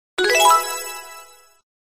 游戏胜利音效.mp3